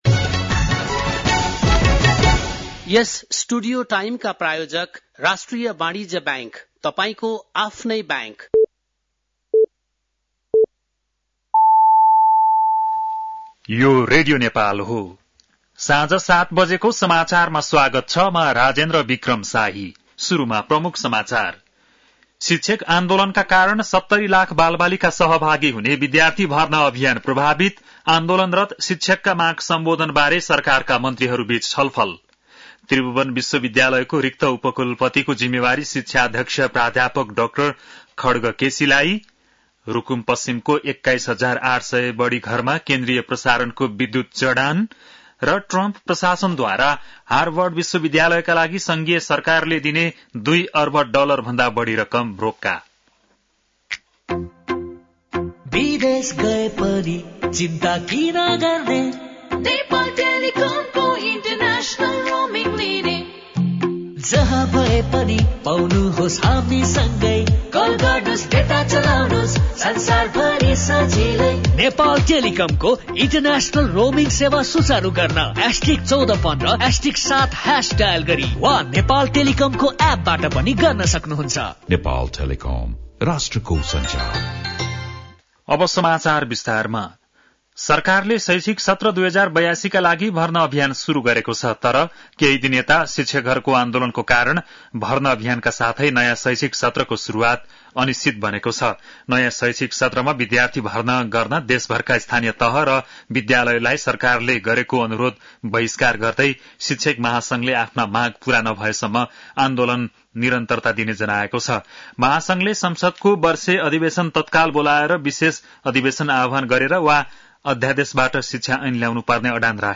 बेलुकी ७ बजेको नेपाली समाचार : २ वैशाख , २०८२